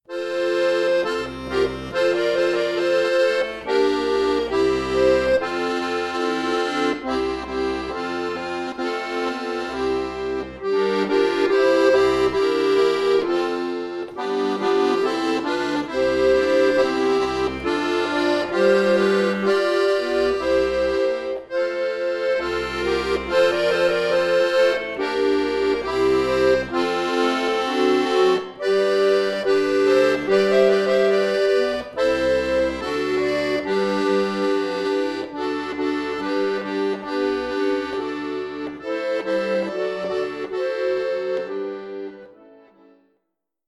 Traditionelles Weihnachtslied für Groß und Klein
Akkordeon Solo
fröhlich , Kinderlied